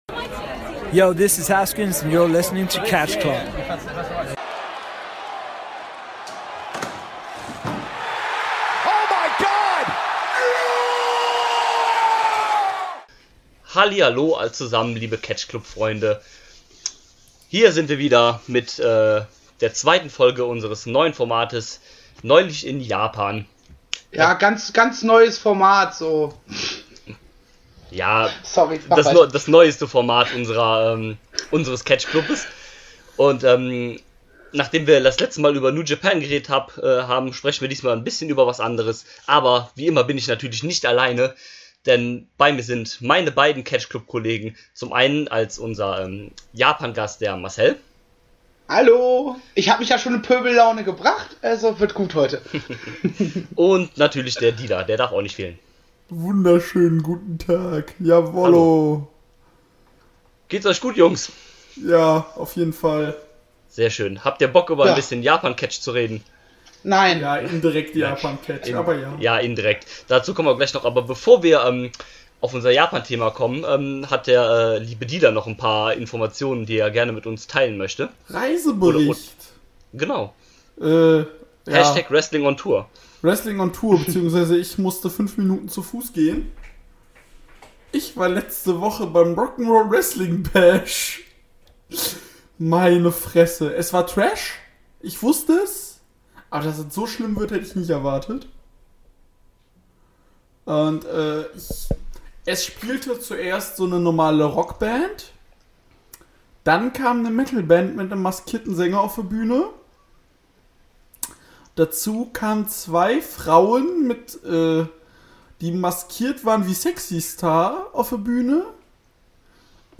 Aufgezeichnet wurde die Folge übrigens bereits Mitte Februar.